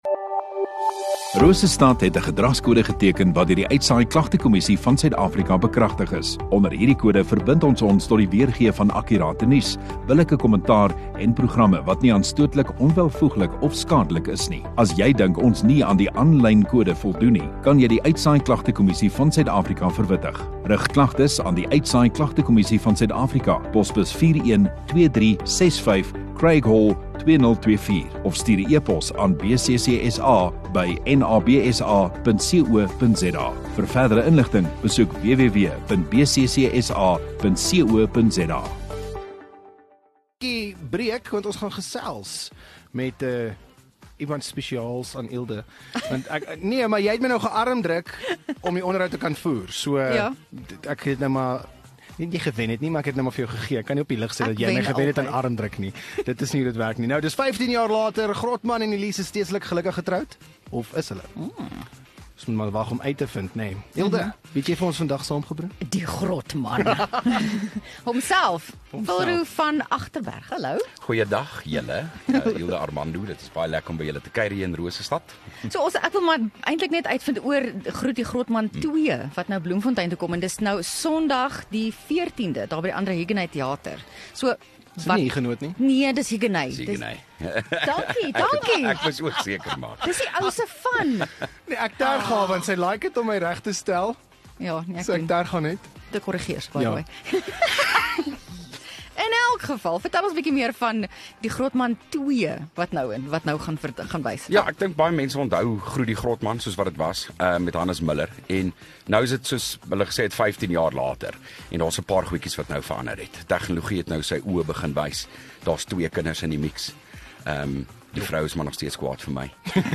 Radio Rosestad View Promo Continue Radio Rosestad Install Kunstenaar Onderhoude 10 Sep Groet die Grotman 2